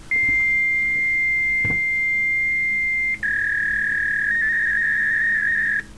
Mustererkennung, Modem-Töne
Beispielsweise sind es bei 1200 Baud die beiden Frequenzen 1200 und 2200 Hz für die Codierung von "0" und "1",
Nun als Hörbeispiel den Beginn des Dialoges zwischen zwei Faxgeräten.
Es gibt ein typisches Muster, dessen akustischer Eindruck sich gut einprägen läßt.